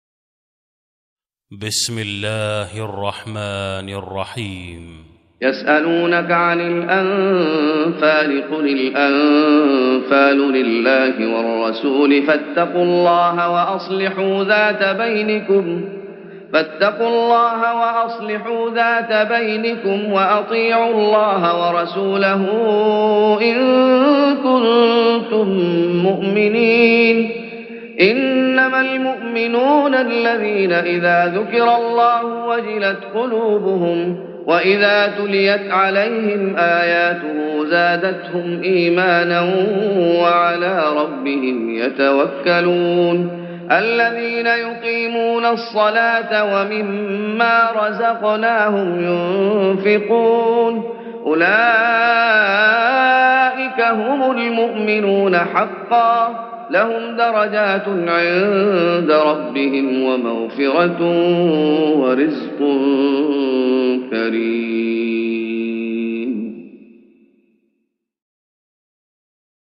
تهجد رمضان 1416هـ من سورة الأنفال (1-4) Tahajjud Ramadan 1416H from Surah Al-Anfal > تراويح الشيخ محمد أيوب بالنبوي 1416 🕌 > التراويح - تلاوات الحرمين